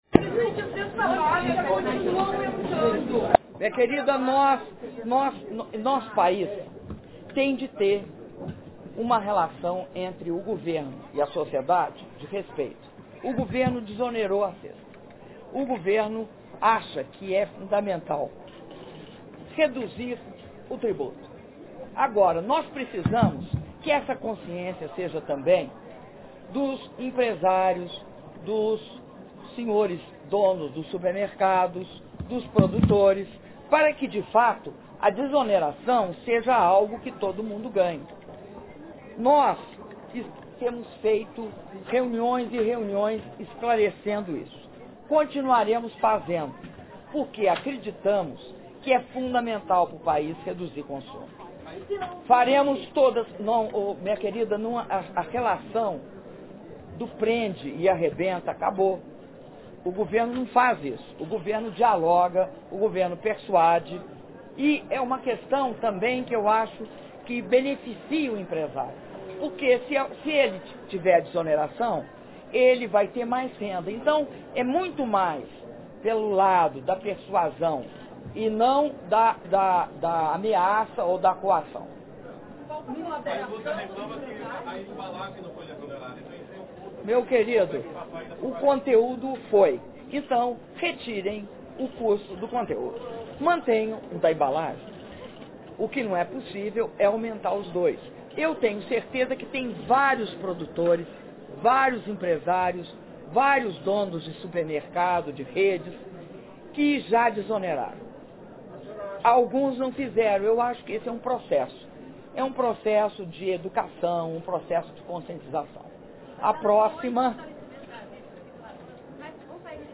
Entrevista coletiva concedida pela Presidenta da República, Dilma Rousseff, após cerimônia de anúncio de Medidas de Proteção ao Consumidor
Palácio do Planalto, 15 de março de 2013